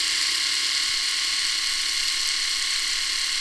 And, in actual fact, it's just an electric shaver recorded for 2 seconds in Audacity!
OK, it's a little on the whiney side, for now.
engine.wav